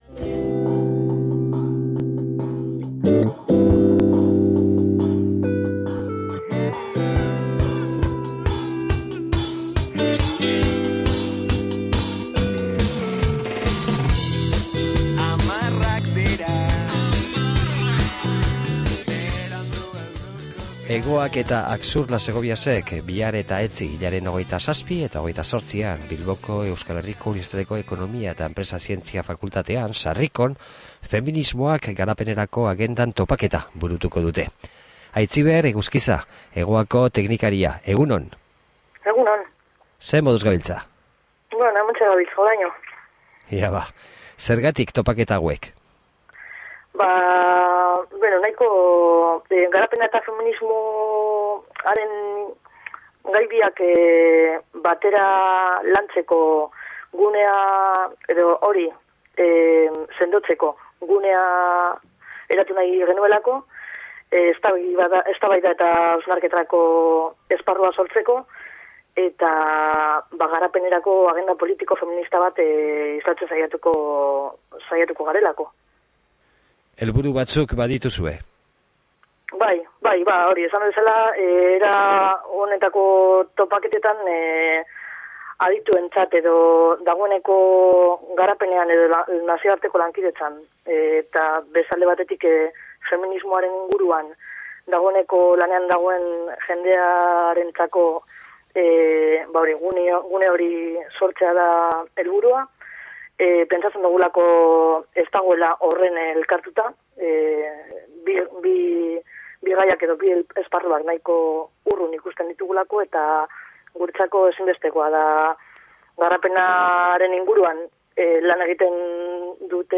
>Elkarrizketa hau deskargatzeko klikatu HEMEN.